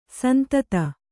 ♪ santata